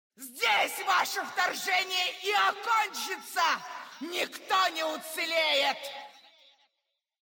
Когда босс применяет какую-нибудь свою способность он издает при этом определенные звуки или говорит «дежурные» фразы, порой весьма раздражающие… smile Рассмотрим это на примере босса Цитадели Ледяной Короны – Синдрагосы.
Начало воздушной фазы во время боя с Синдрагосой: